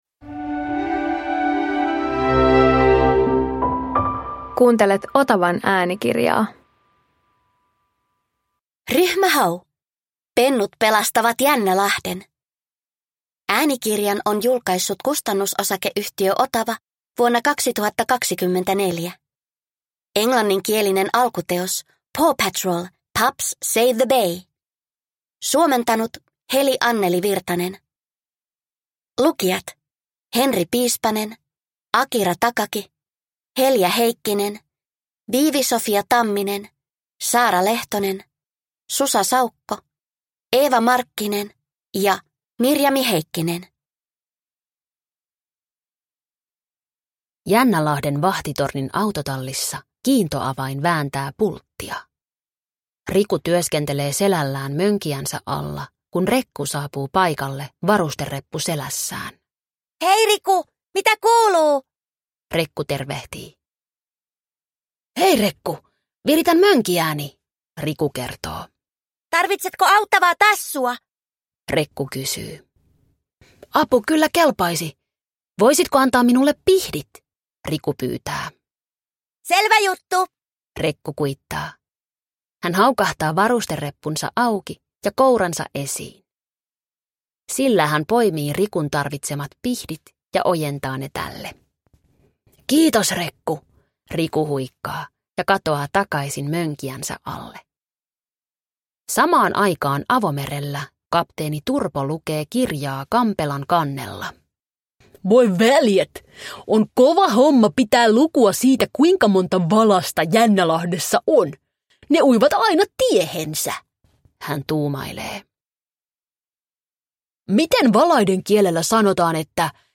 Ryhmä Hau - Pennut pelastavat Jännälahden – Ljudbok